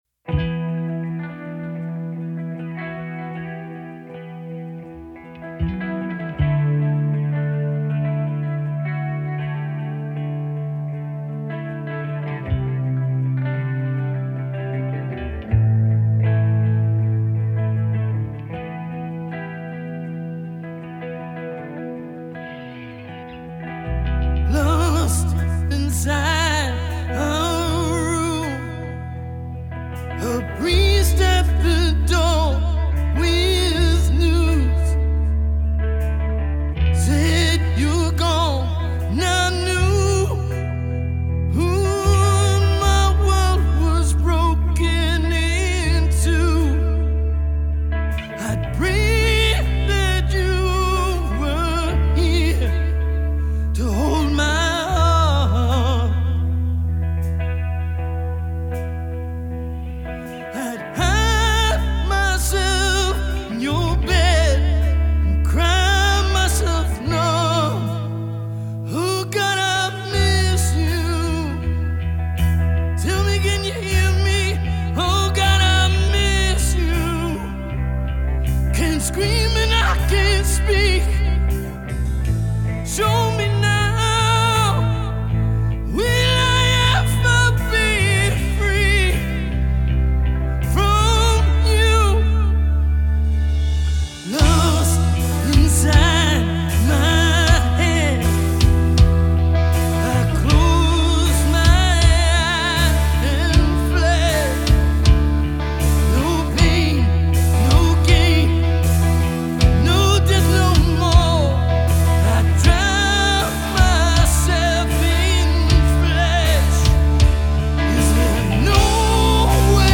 Heavy Metall glam metal